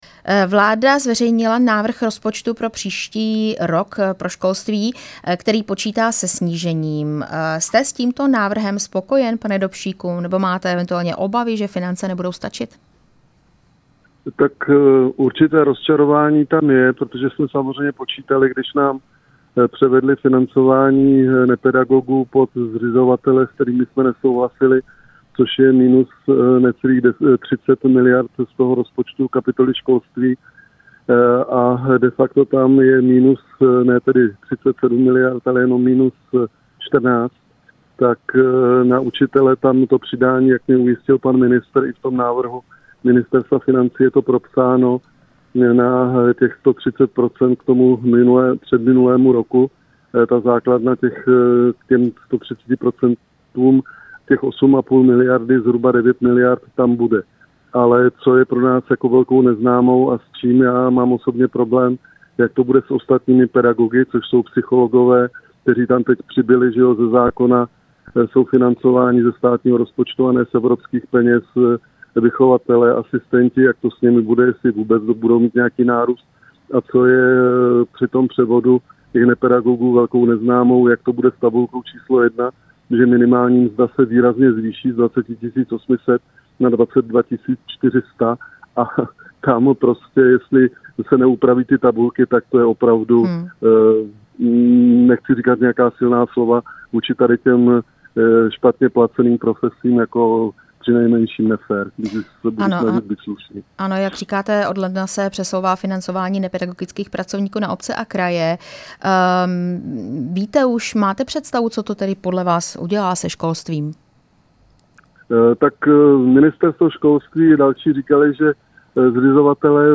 Rozhovor